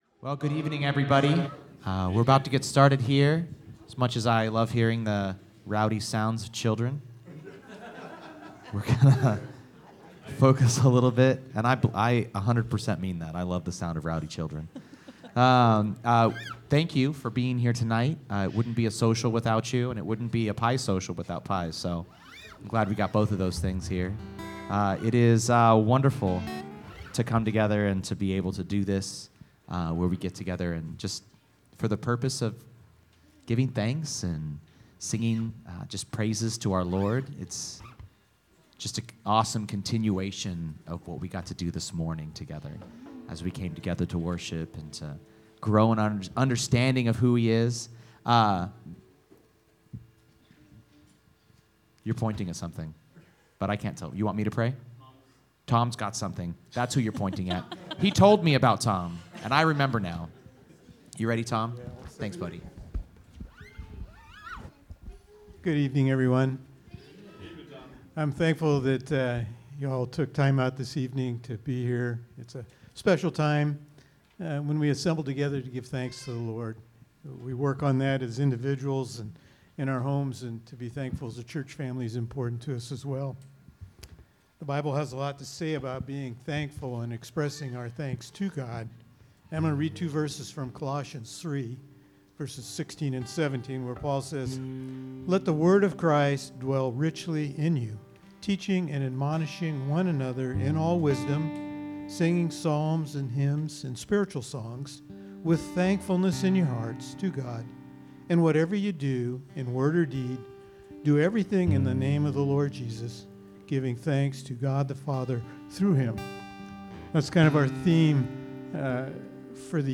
2024 Thanksgiving Worship Service
This special podcast episode is the audio from this year's Thanksgiving worship service, which was quickly followed by a pie social. While it's too late for the pie, please join us in a time of worshipping God and praising Him for the many blessings He has poured out on us.